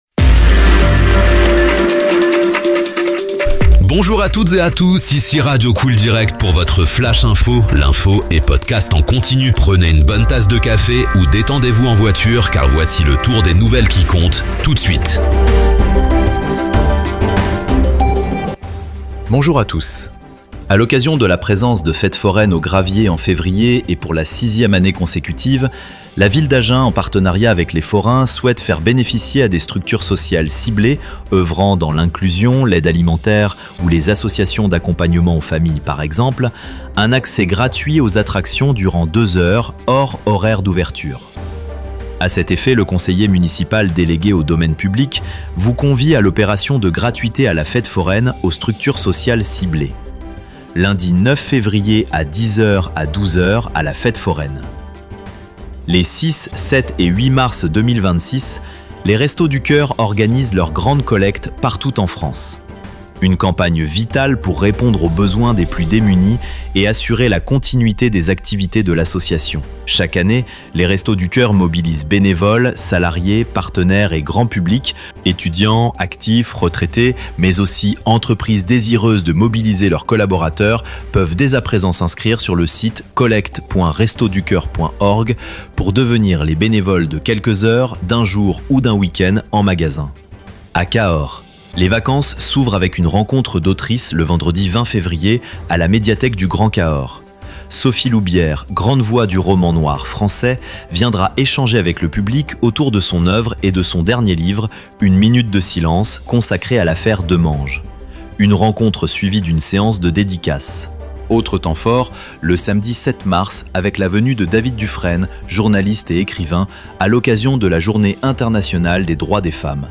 Flash infos 10/04/2026
L'infos sur radio COOL DIRECT de 7h à 21h